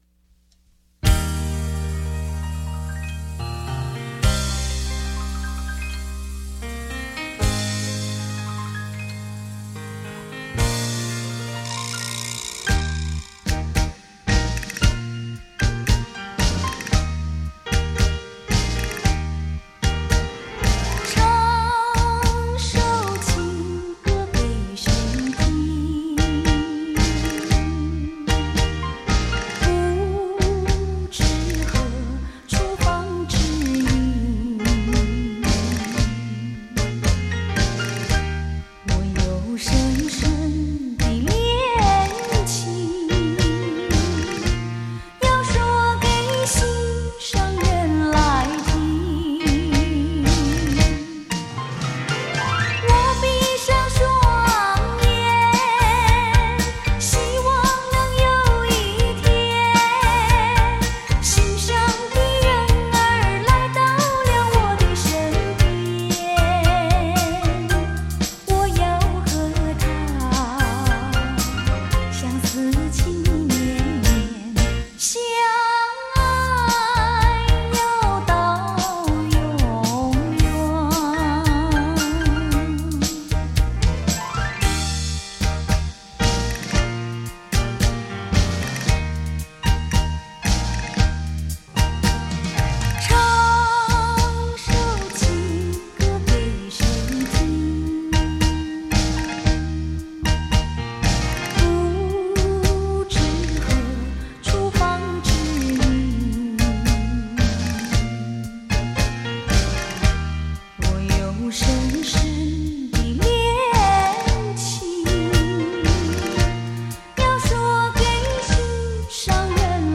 双钢琴现场演奏，熟悉好歌精选